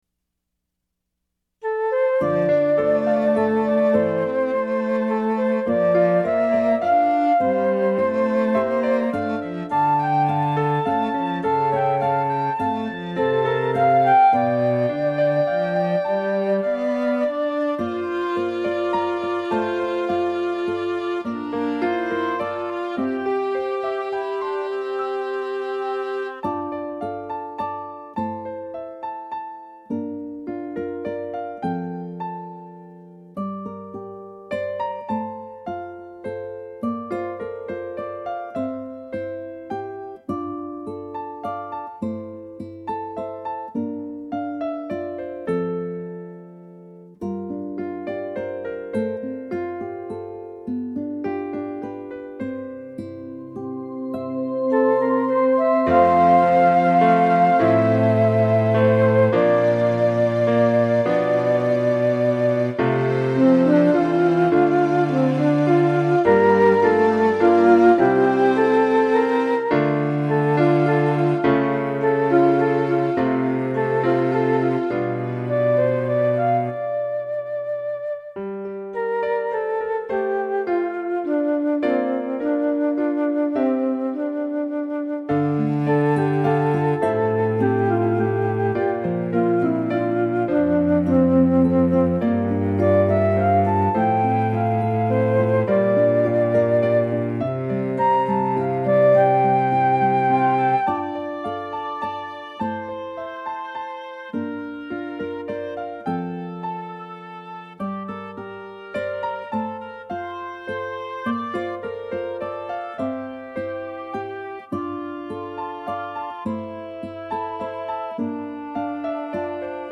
The arrangement was minimal and unfinished, but the magic was there.
I listened to it over and over and each time the minor chords swept through my heart, it left me euphoric.